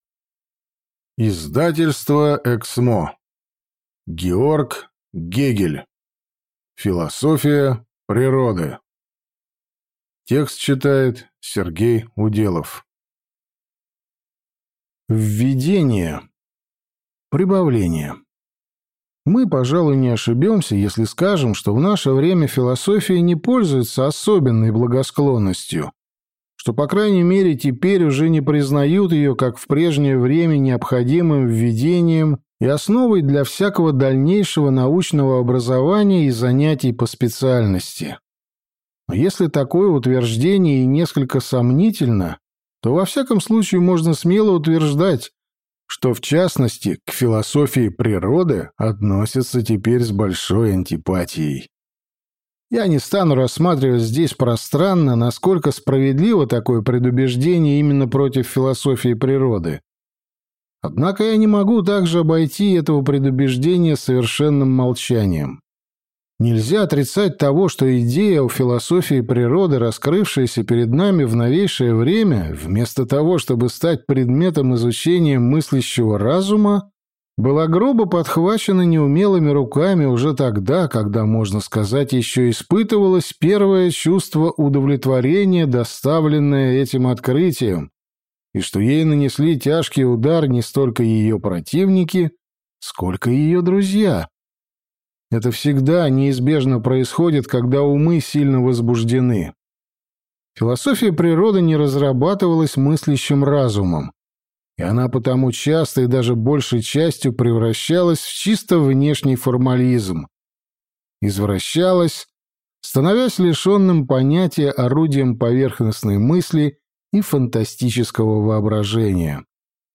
Аудиокнига Философия природы | Библиотека аудиокниг